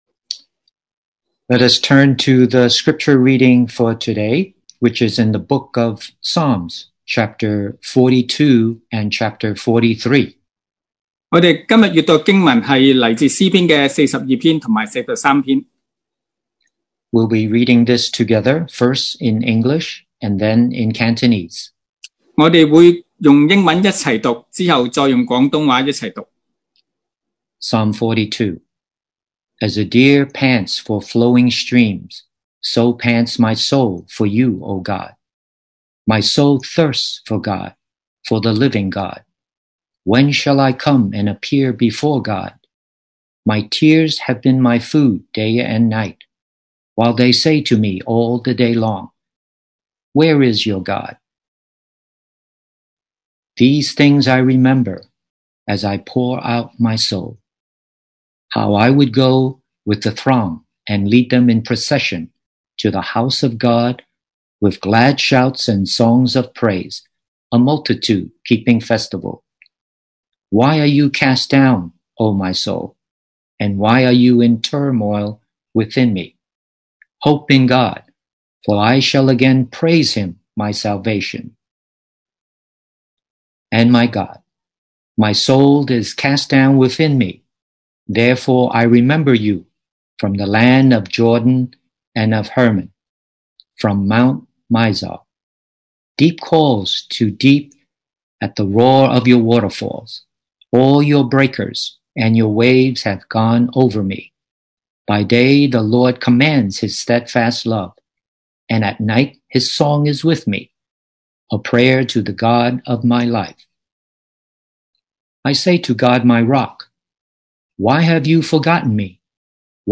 2020 sermon audios
Passage: Psalm 42:1-43:5 Service Type: Sunday Morning